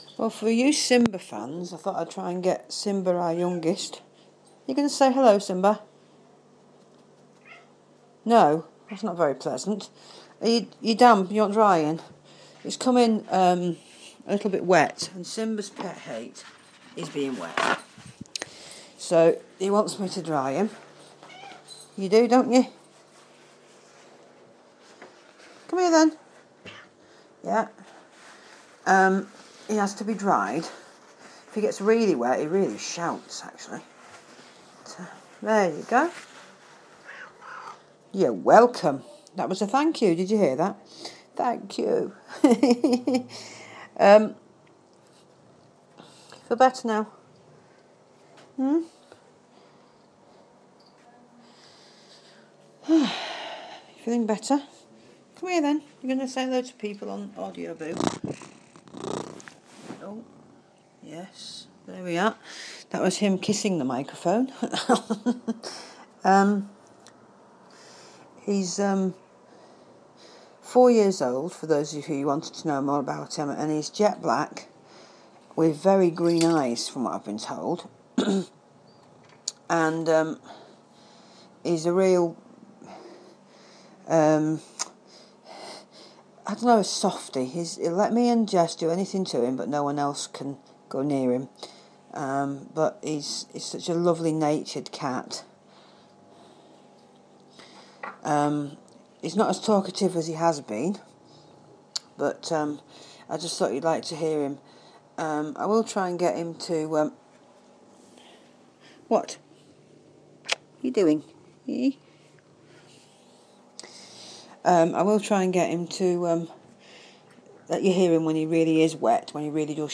simba the cat's boo for the day